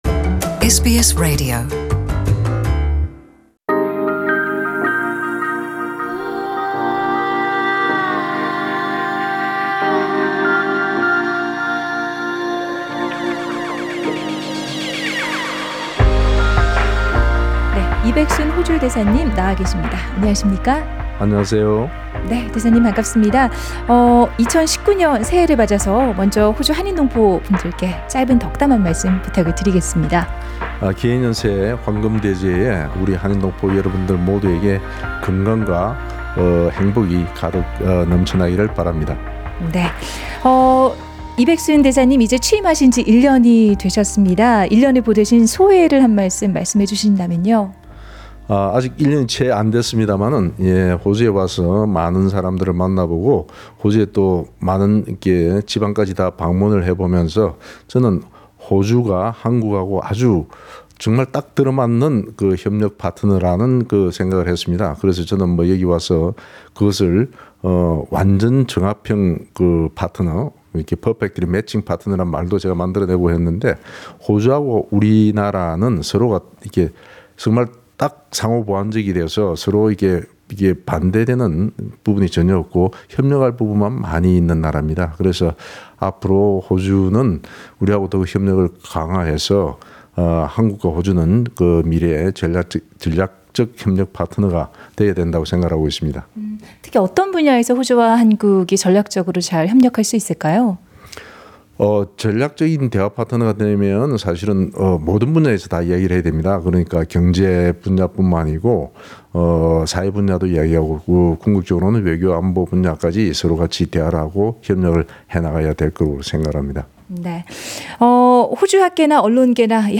SBS 한국어 프로그램은 새해를 맞아 이백순 주 호주 한국 대사와 함께 북한의 비 핵화와 인권 문제 등에 대해 대담을 나눴다.
His Excellency Mr. Baek-Soon Lee, Ambassador of the Republic of Korea to Australia speaks to SBS Korean program.